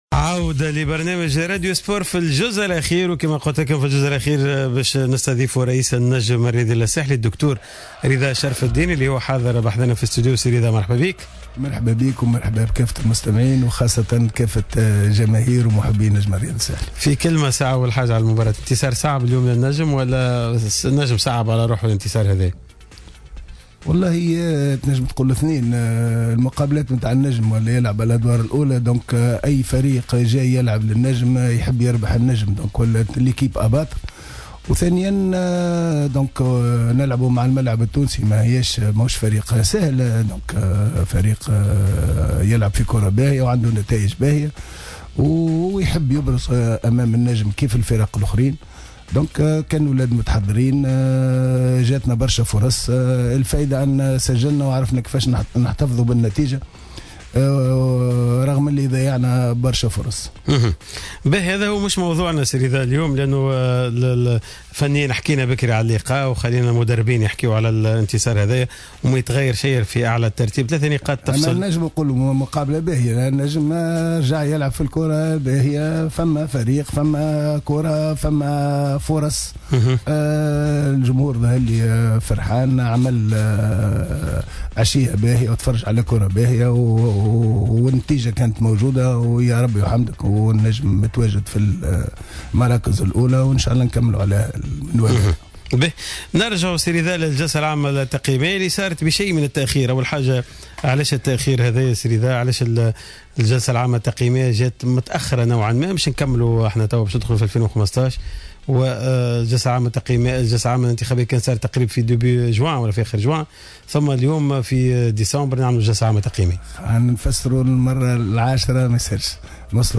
خلال استضافته مساء اليوم الأحد 14 ديسمبر 2014 في راديو سبور تحدث رئيس النجم الساحلي رضا شرف الدين عن الجلسة العامة التقييمية للفريق .